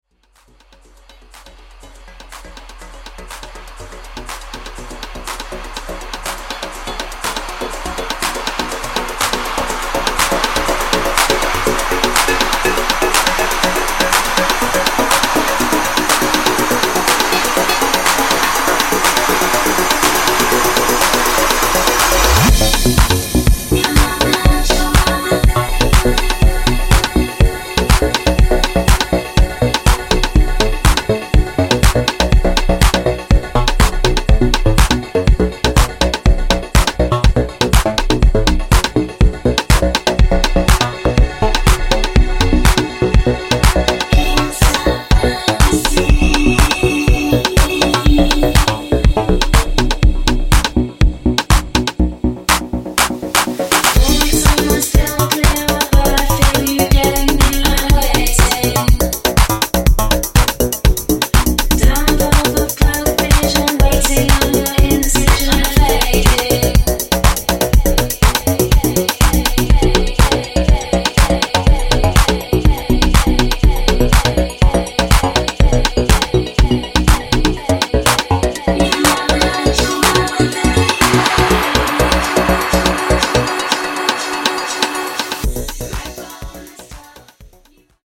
offering a classy and solid texture.